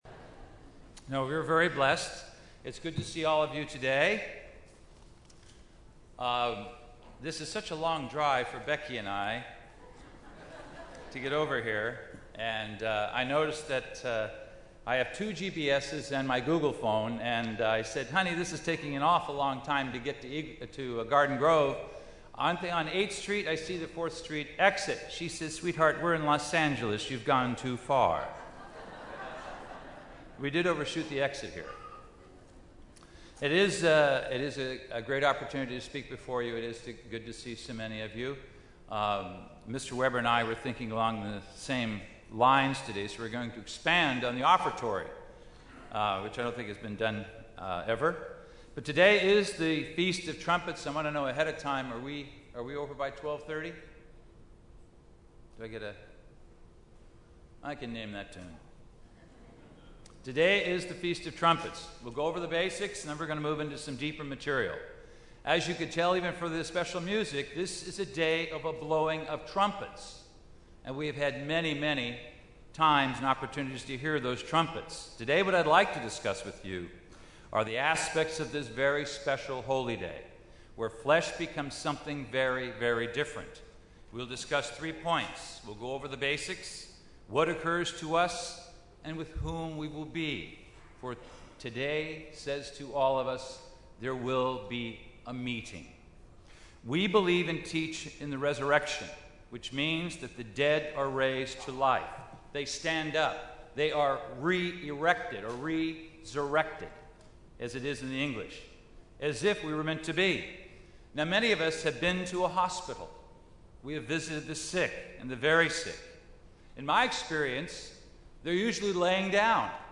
This message, given on the Feast of Trumpets, discusses several important aspects of the meaning of this very special Holy Day - including what will occur to us in the future, with whom it will be, and also delves into the related typology in the account of Abraham's binding of Isaac.
Given in Los Angeles, CA
UCG Sermon Studying the bible?